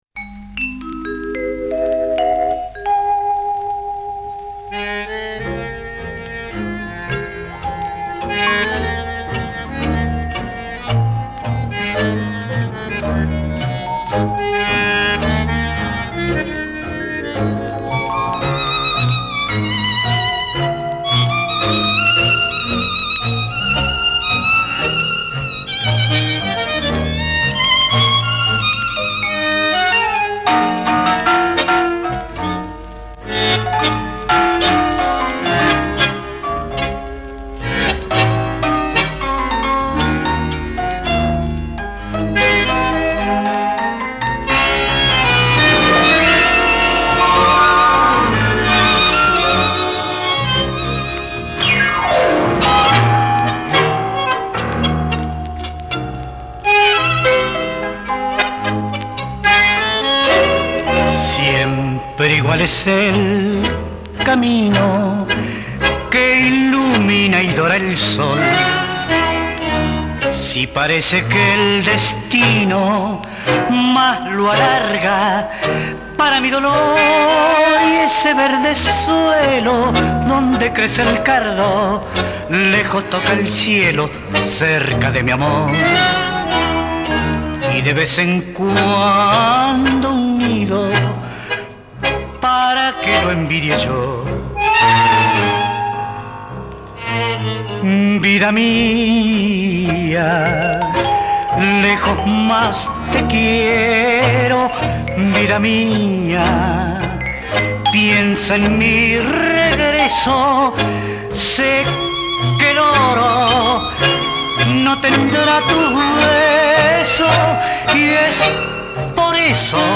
Genre: Tango